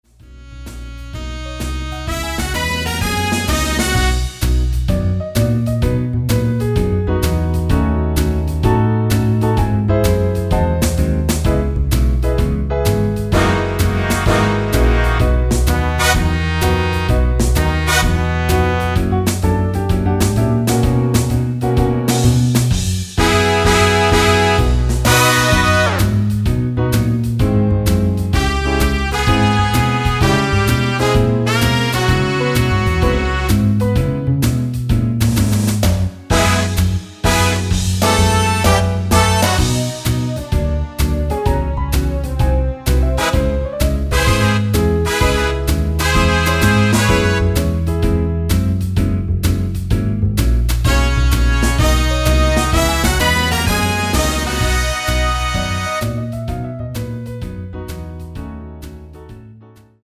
간주가 길어서 축가등에 사용하시기 좋게 간주를 4마디로 편곡 하였습니다.(미리듣기 참조)
원키 간주짧게 편곡한 MR입니다.(미리듣기 확인)
앞부분30초, 뒷부분30초씩 편집해서 올려 드리고 있습니다.